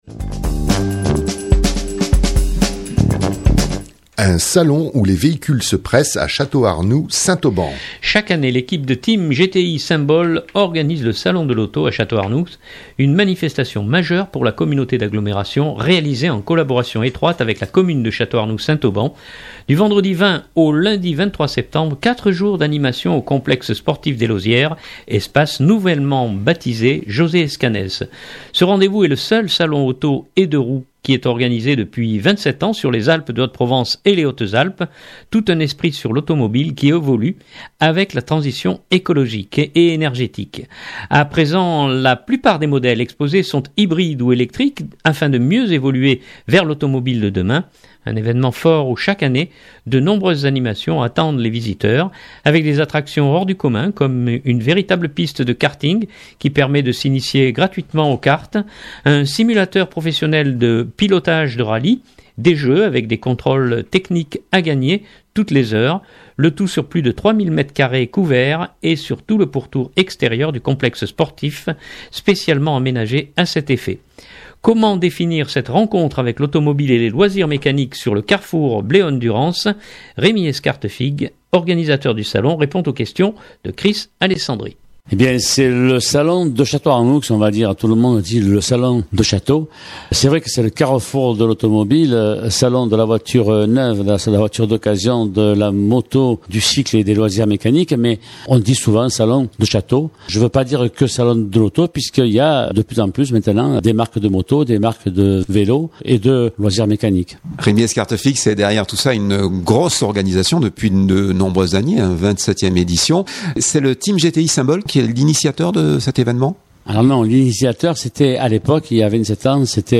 Comment définir cette rencontre avec l’automobile et les loisirs mécaniques sur le carrefour Bléone Durance ?